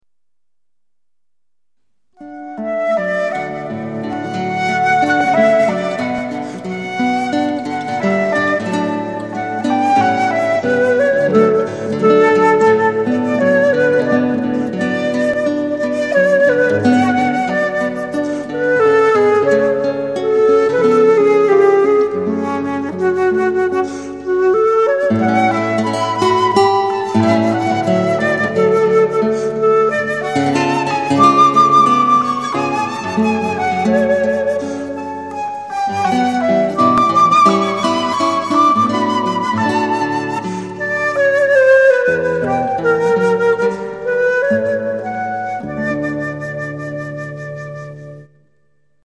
Zvuková adaptace jevištního recitálu
flétna
kytara